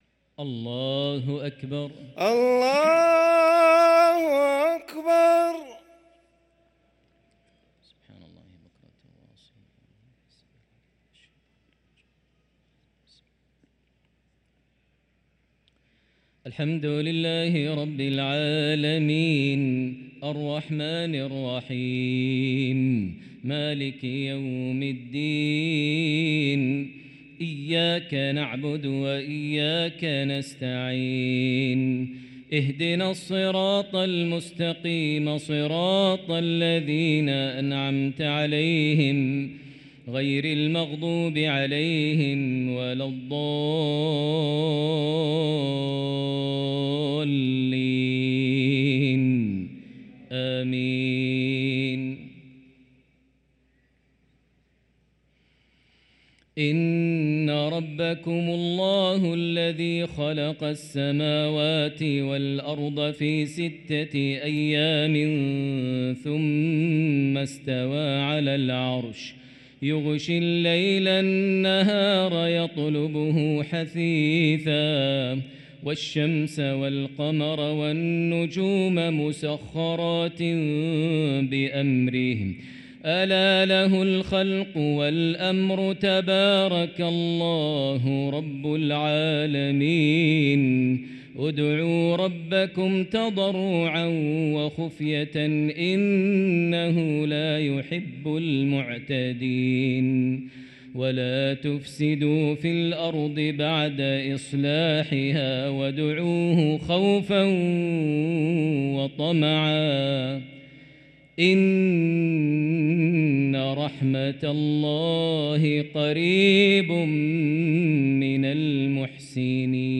صلاة العشاء للقارئ ماهر المعيقلي 6 صفر 1445 هـ
تِلَاوَات الْحَرَمَيْن .